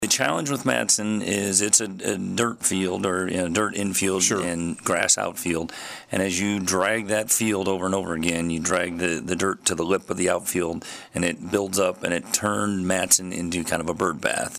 City Manager Mike Schrage joined in on the KSAL Morning News Extra with a look at fixing the drainage problems that have plagued the grass and dirt ball diamond on Markley Road.